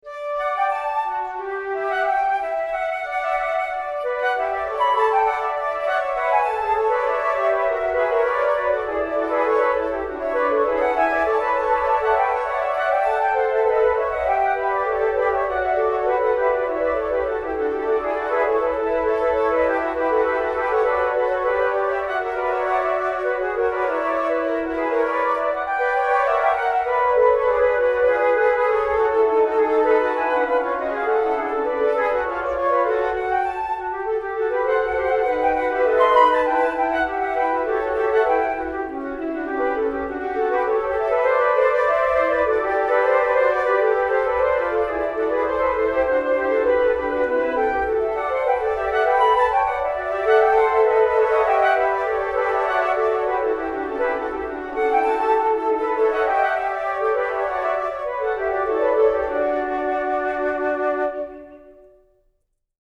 - 3 flutes